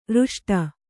♪ ruṣṭa